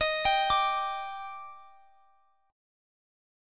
EbM7 Chord
Listen to EbM7 strummed